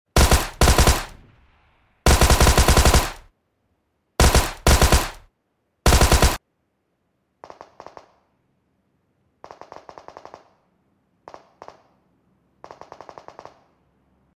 Parte 4| Thompson IN LONG sound effects free download
Parte 4| Thompson IN LONG RANGE SOUND